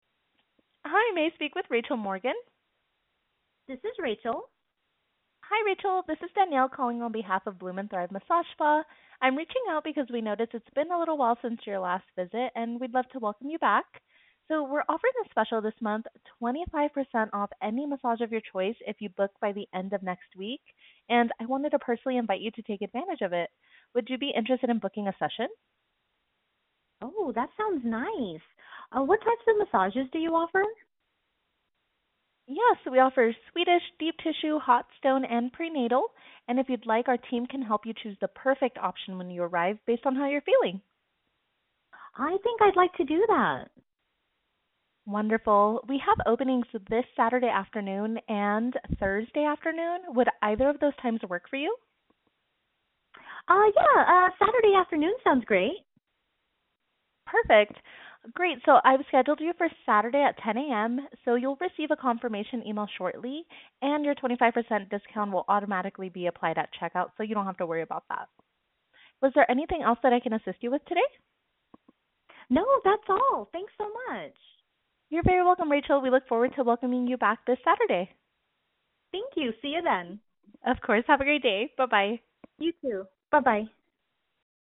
Custom Campaign Call
HUMAN RECEPTIONIST
Custom-Campaign-Call-Human.mp3